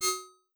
GenericNotification6.wav